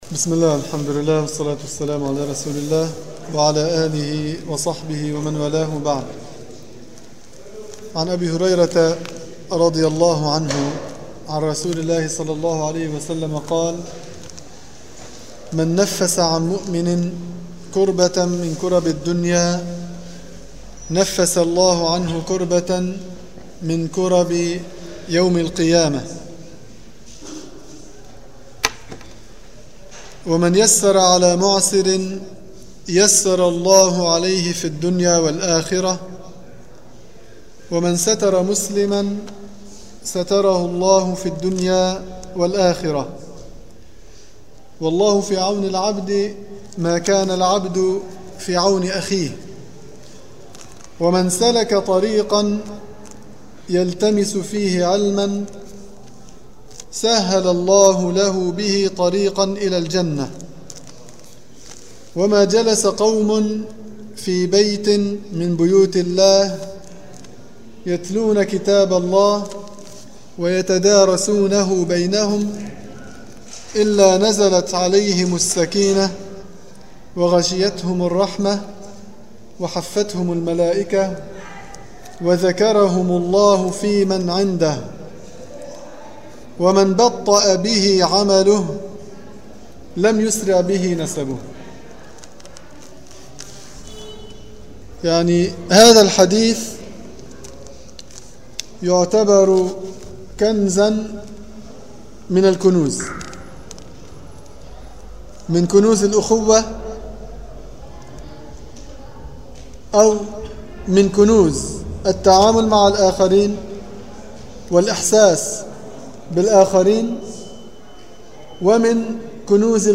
المكان: مسجد القلمون الغربي
دروس تفسير الحديث